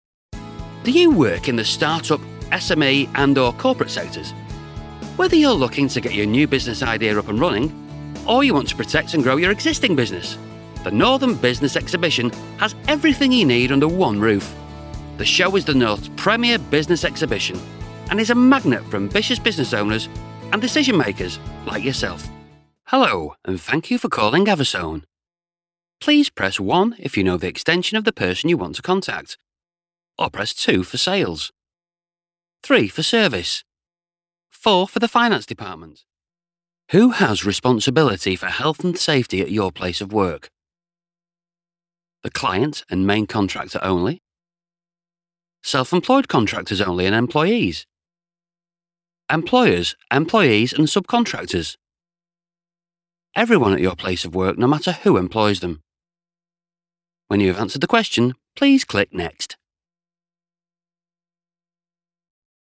Corporate Showreel
Down to earth, friendly Northern actor.
Male
Manchester
Friendly